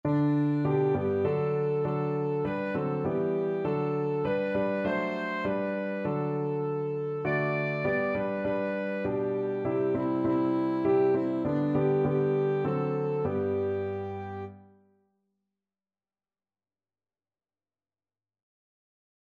Alto Saxophone
3/4 (View more 3/4 Music)